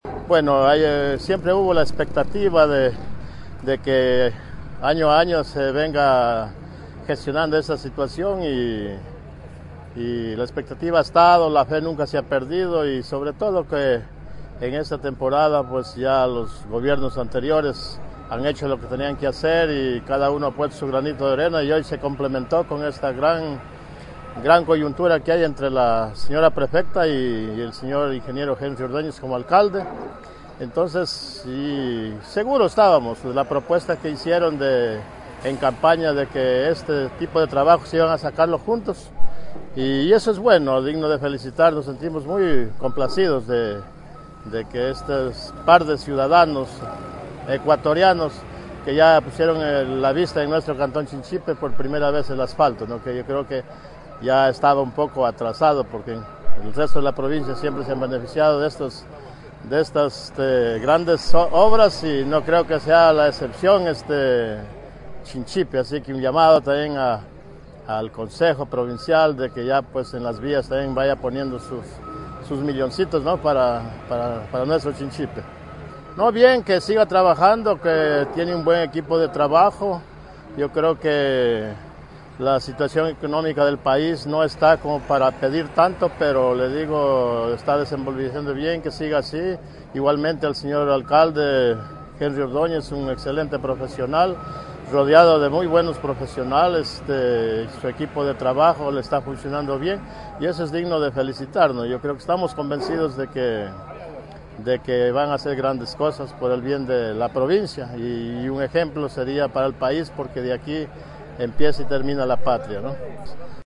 HABITANTE DE CHINCHIPE